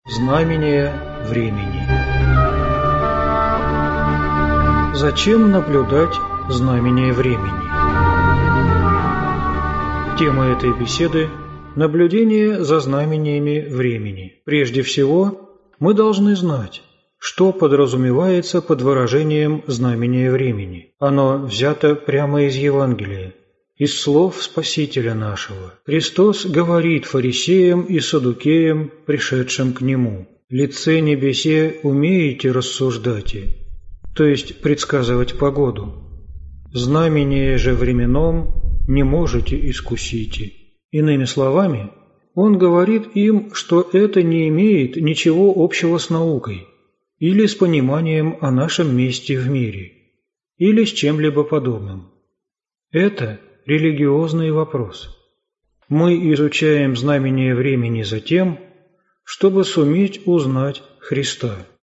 Аудиокнига Знамения времени | Библиотека аудиокниг
Прослушать и бесплатно скачать фрагмент аудиокниги